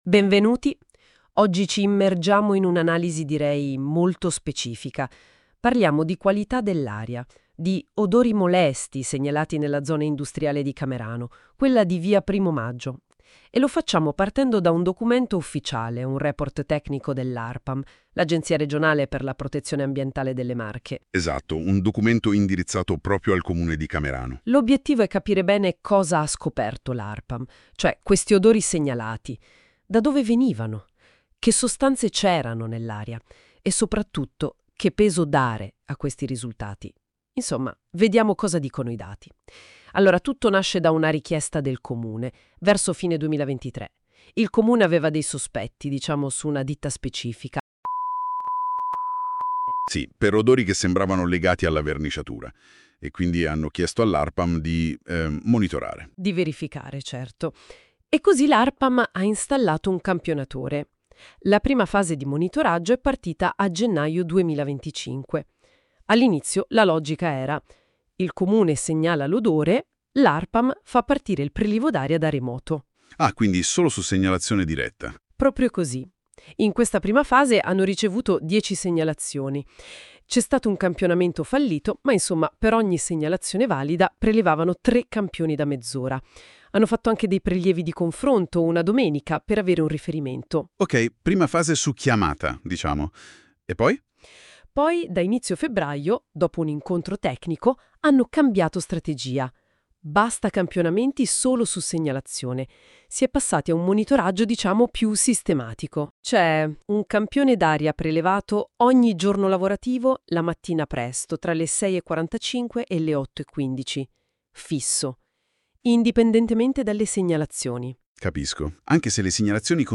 intervista-1.mp3